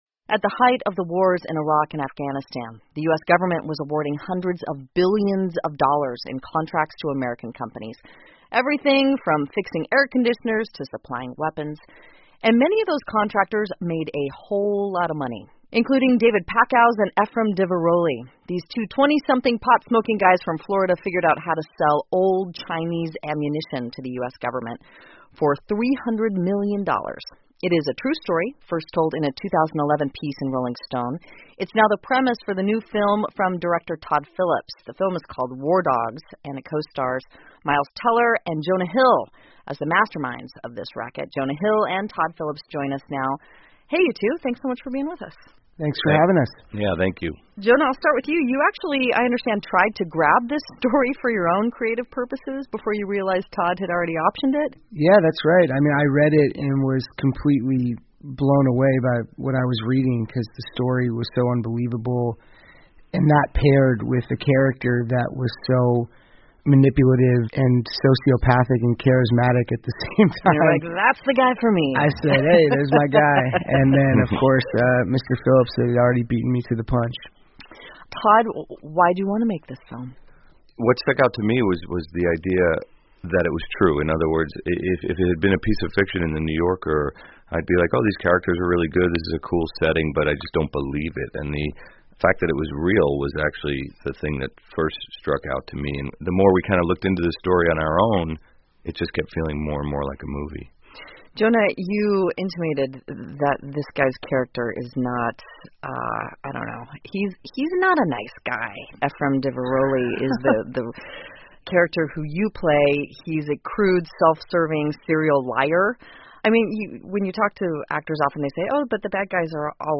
美国国家公共电台 NPR Interview With Jonah Hill And Todd Phillips On New Film 'War Dogs' 听力文件下载—在线英语听力室